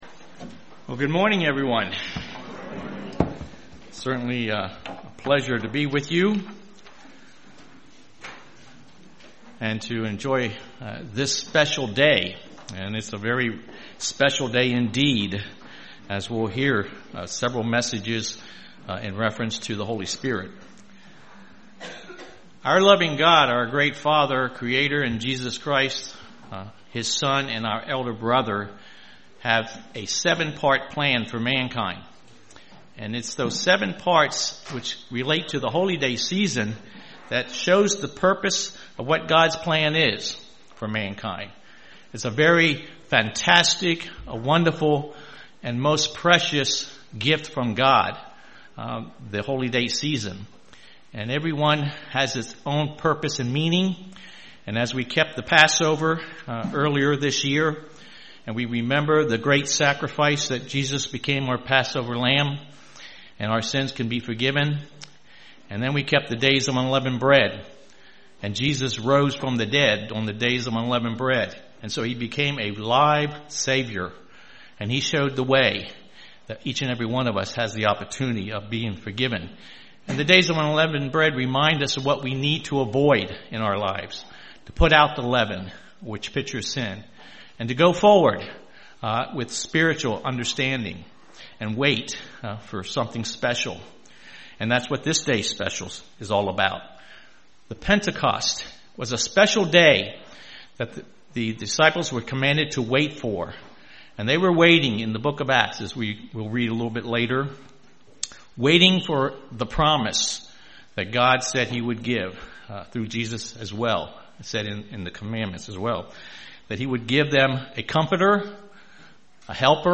Given in Lehigh Valley, PA